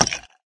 woodice2.ogg